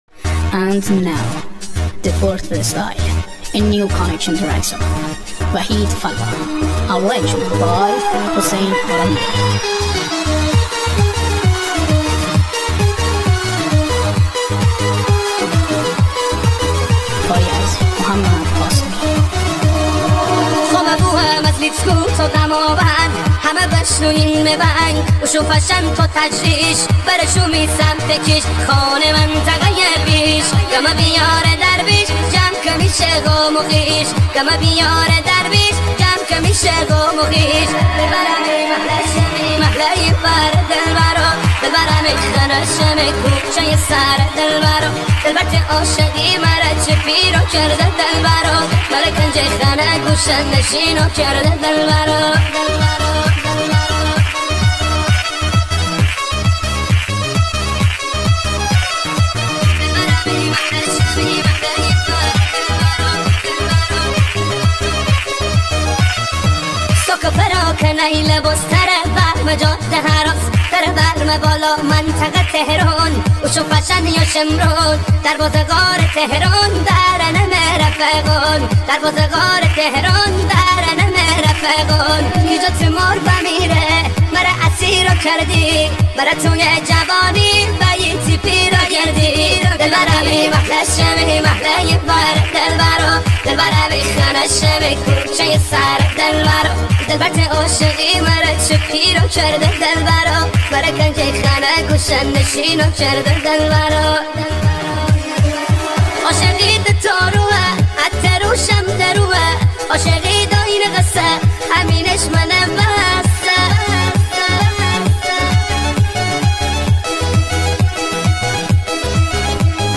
با صدای بچه بچگانه